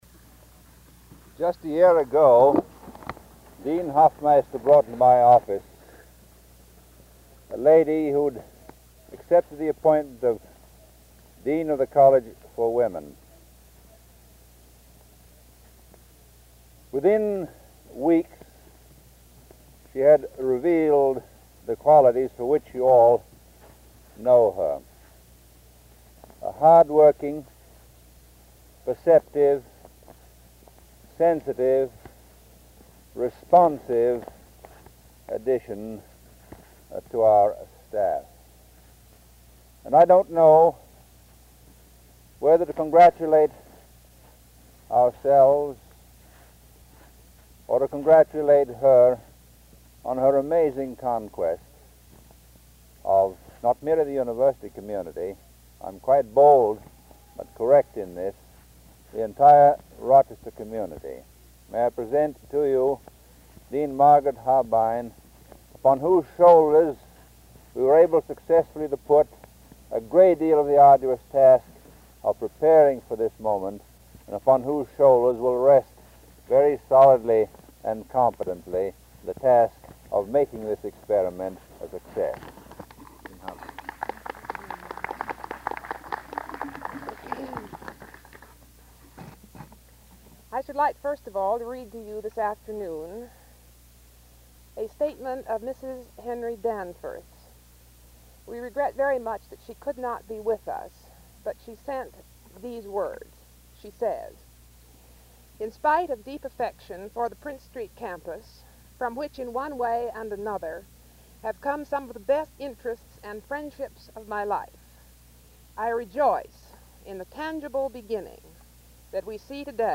1952 Groundbreaking for Susan B. Anthony Hall and Spurrier Gymnasium
Remarks